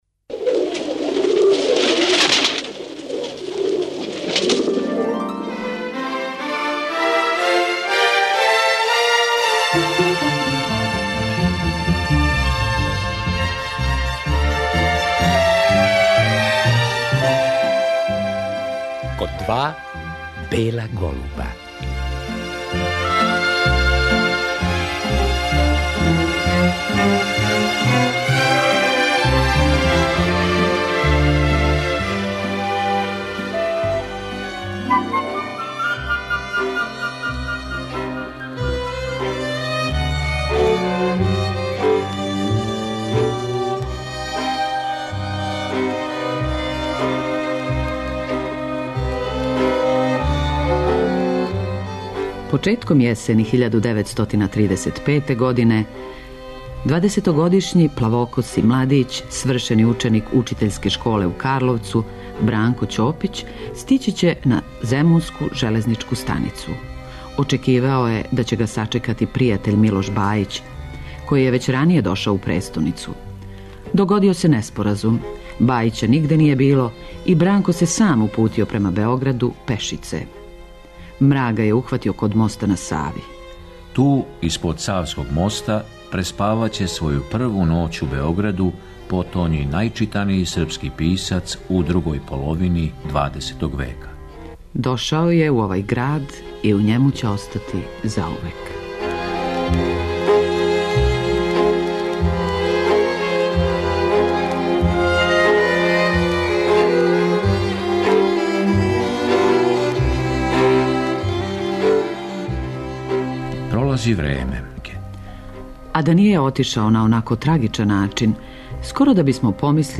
Захваљујући многобројним тонским и новинарским записима, у прилици смо да чујемо како се писац сећао неких времена из свога живота, а нарочито детињства.